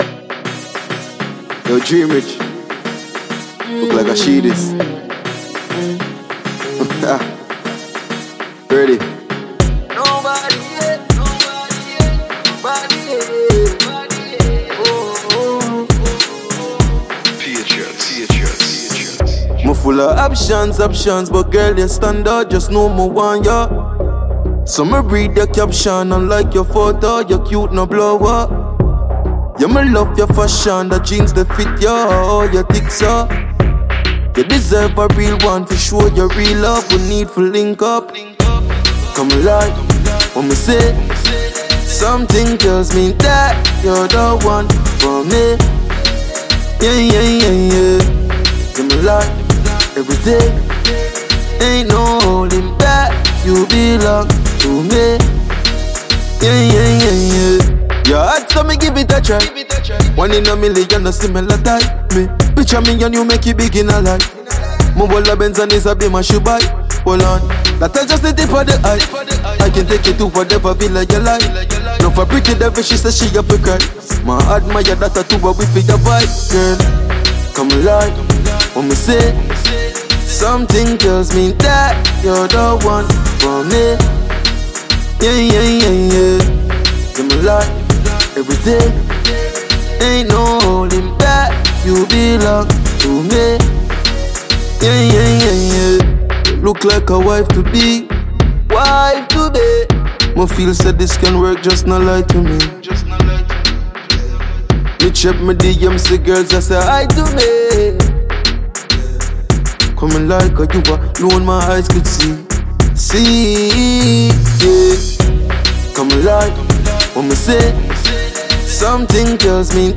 Dancehall/HiphopMusic
dancehall